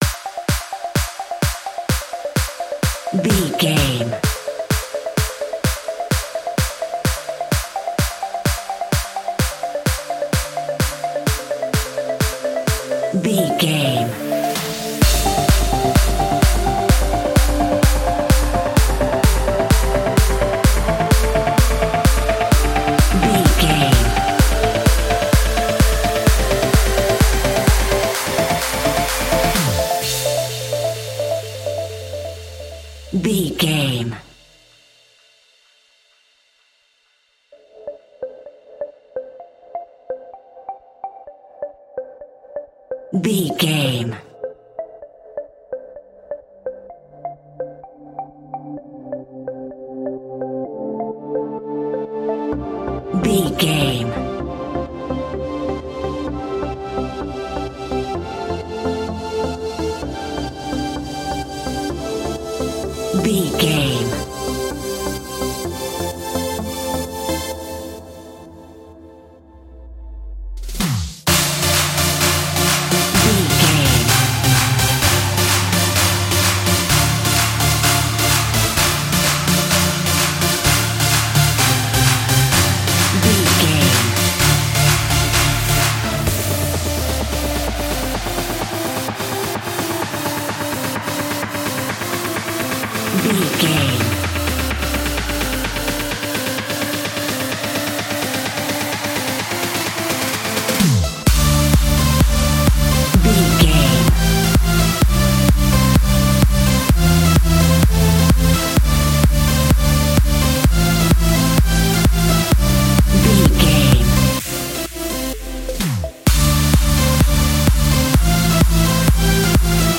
Ibiza Trance.
Aeolian/Minor
D
Fast
energetic
hypnotic
uplifting
synthesiser
drum machine
electronic
uptempo
synth leads
synth bass